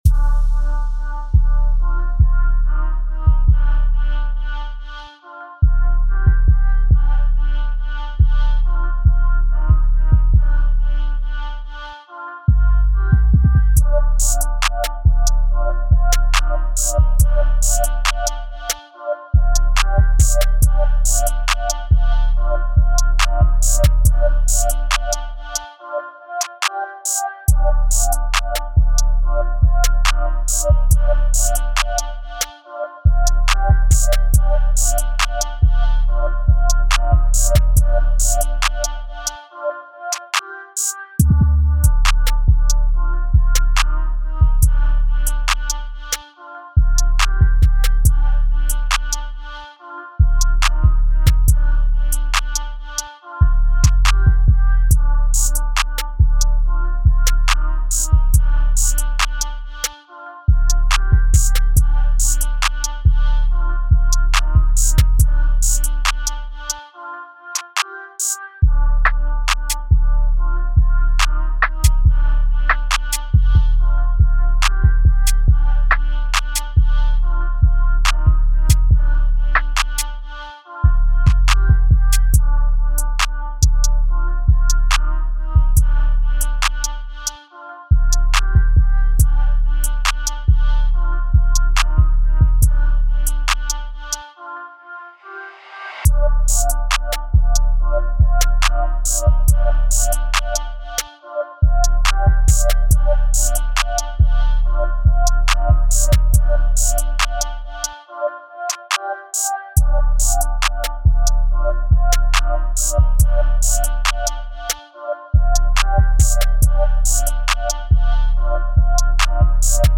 Trap Beats